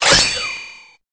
Cri de Monorpale dans Pokémon Épée et Bouclier.